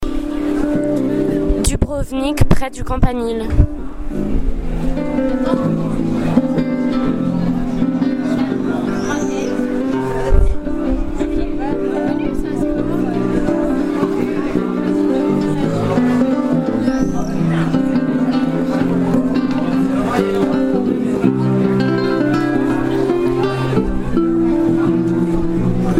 ambiance sonore près du campanile.